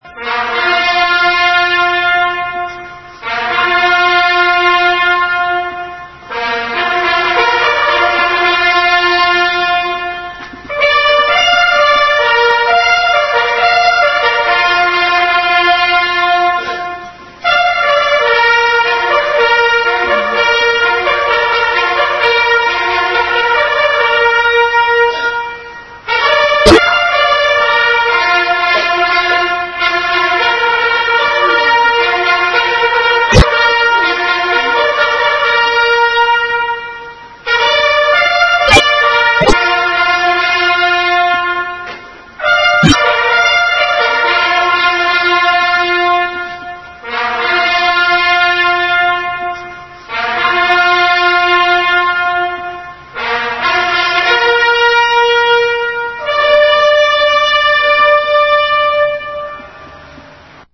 trompetsolo, die bij droevige gelegenheden - onder meer tijdens Dodenherdenking – ten gehore wordt gebracht?